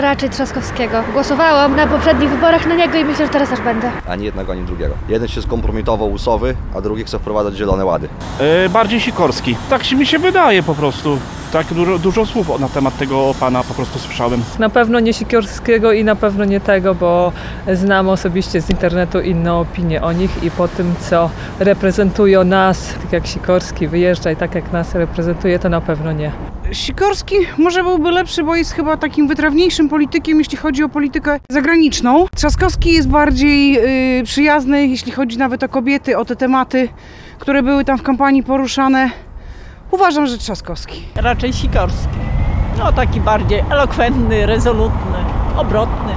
Zapytaliśmy przechodniów na ulicach Suwałk, kto ich zdaniem będzie lepszym kandydatem na prezydenta.